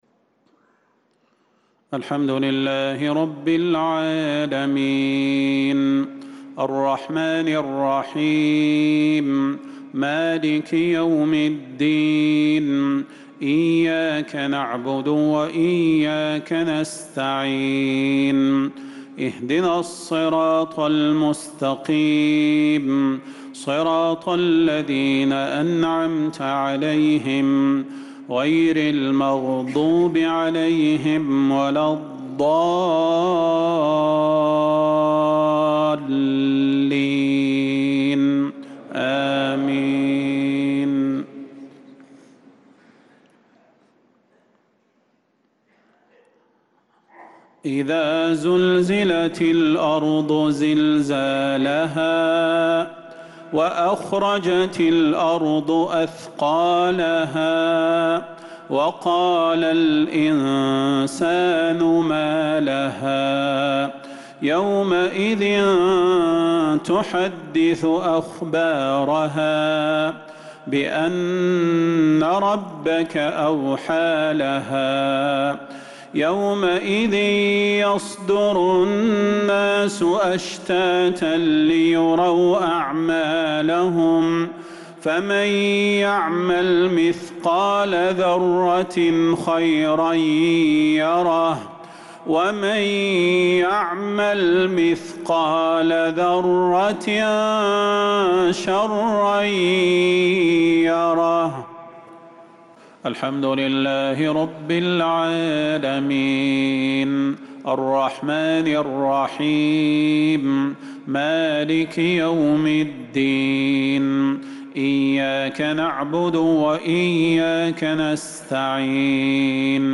صلاة المغرب للقارئ صلاح البدير 19 ذو القعدة 1445 هـ
تِلَاوَات الْحَرَمَيْن .